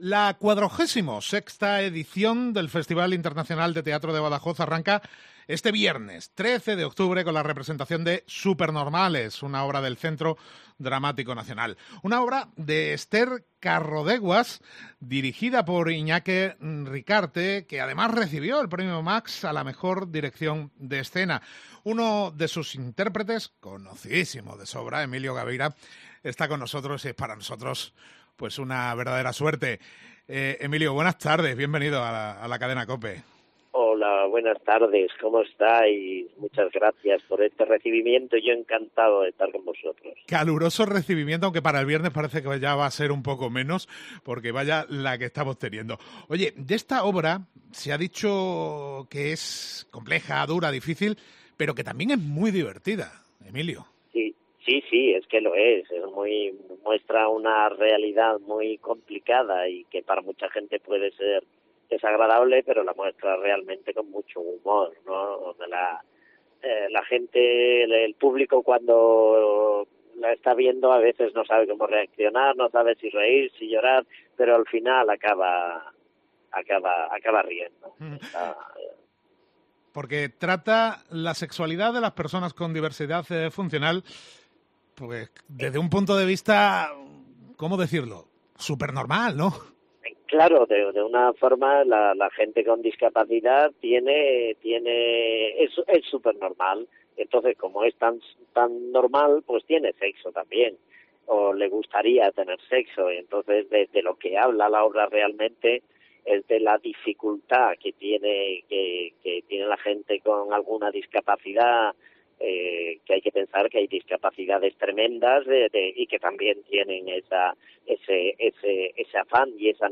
Entrevista con Emilio Gavira, actor en "Supernormales"